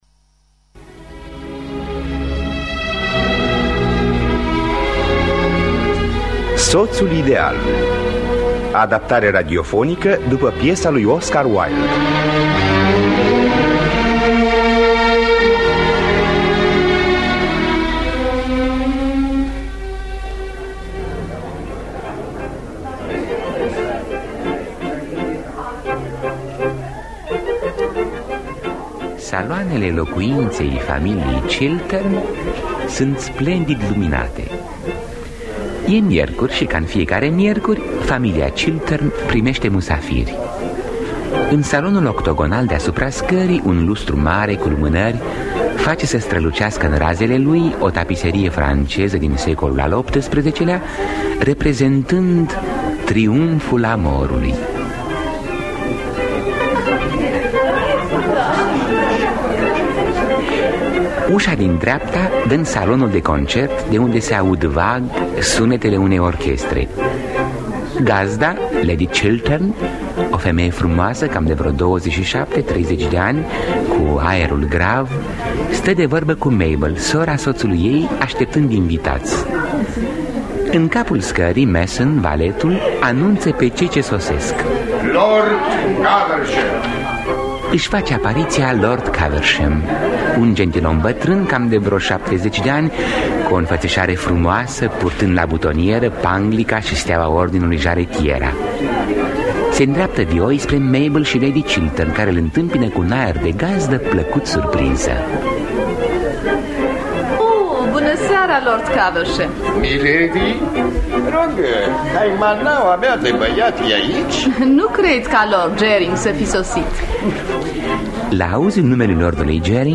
Soțul ideal de Oscar Wilde – Teatru Radiofonic Online